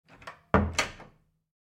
SFX咚哒关门声音效下载
这是一个免费素材，欢迎下载；音效素材为咚哒关门声音效， 格式为 mp3，大小1 MB，源文件无水印干扰，欢迎使用国外素材网。